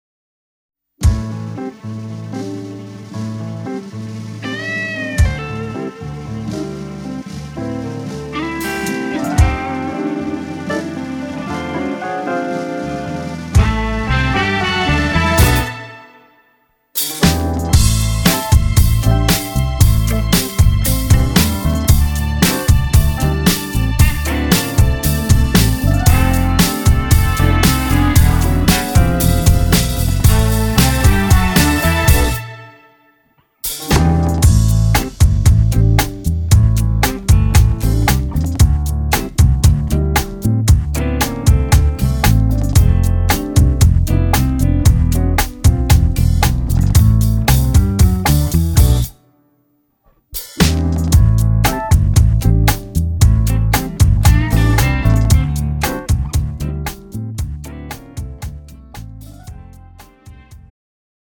음정 -1키
장르 가요 구분 Premium MR